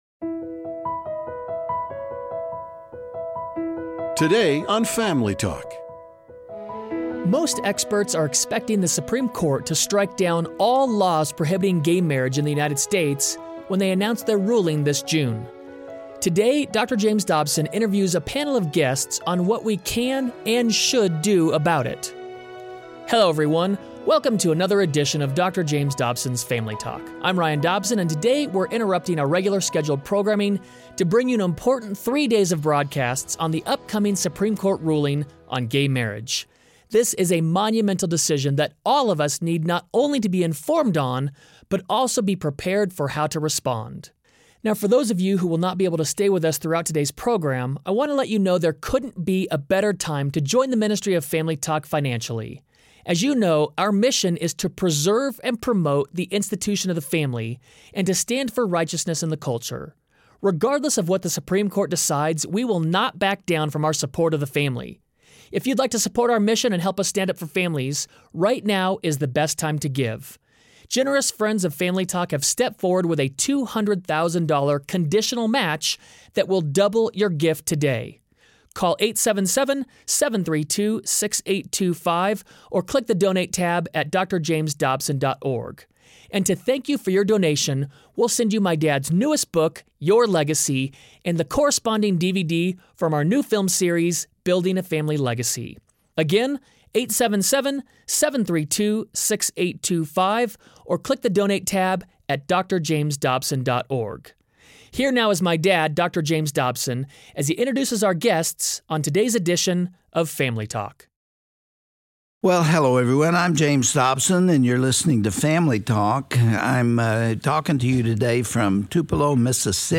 Most experts are expecting the Supreme Court to strike down all laws prohibiting gay marriage in the United States when they announce their ruling this June. On the next edition of Family Talk, Dr. James Dobson interviews a panel of guests on what we can and should do about it.